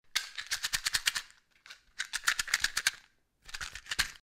Звуки спички, коробка
Звук коробка спичек